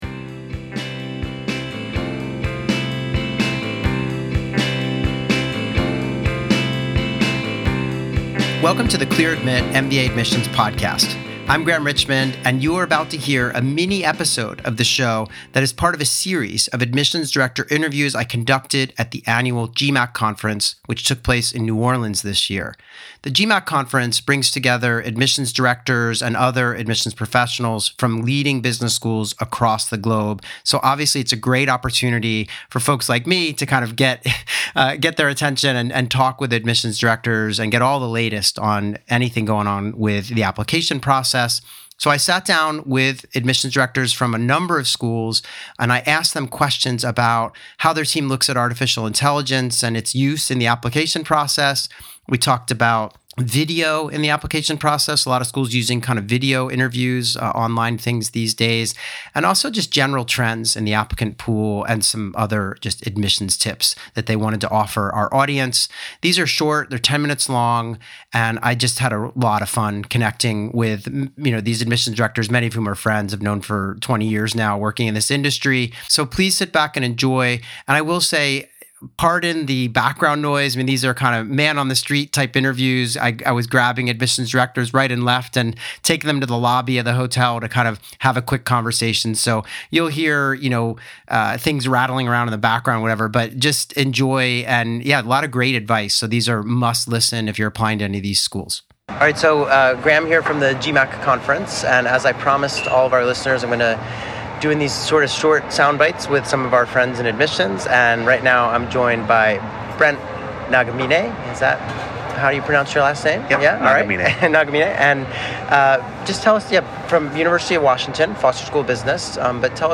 Live Admissions Q&A
joins the Clear Admit MBA Admissions Podcast live from the GMAC Conference.